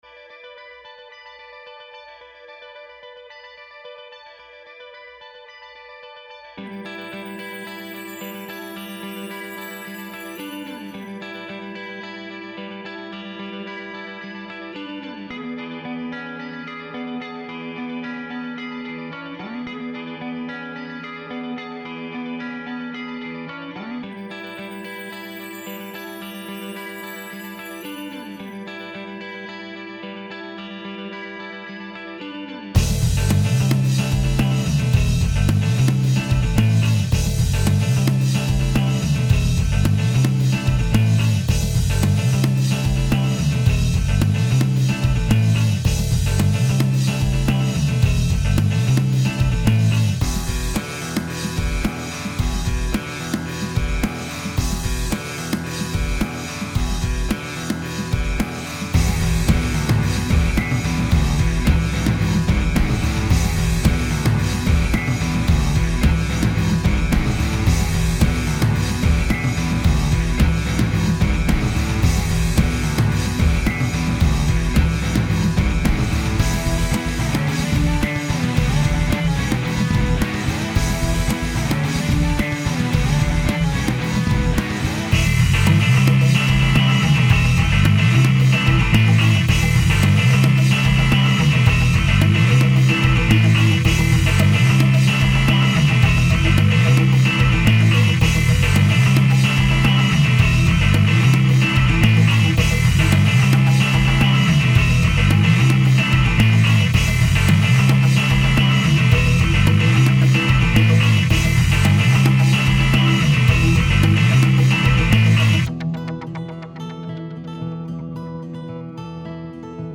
MOONPATROL - HIGH ENERGY ROCK'N'ROLL
Klampfe einstecken, Schlagzeug aussuchen und Aufnahme drücken.
Damit wäre die Stimmung der Nacht dann eingefangen und die Wache wurde zum 6-Stunden-Jam mit den Maschinen.